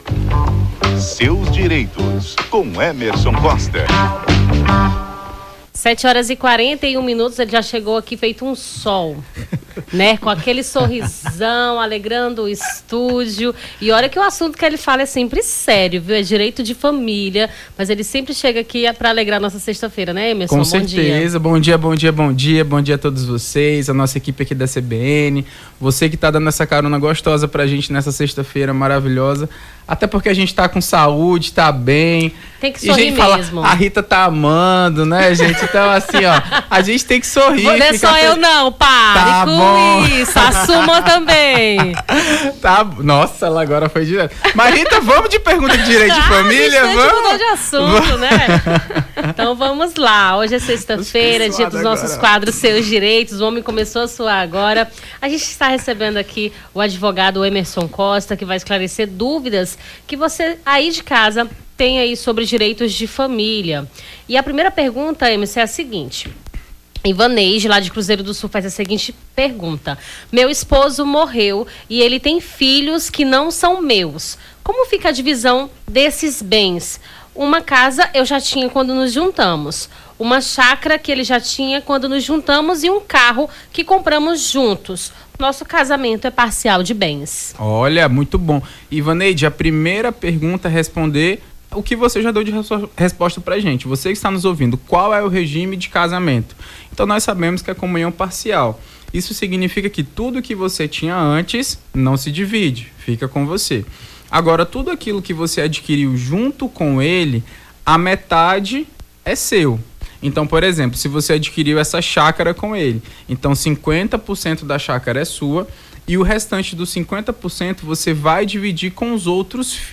Seus direitos: advogado tira dúvidas dos ouvintes sobre direito de família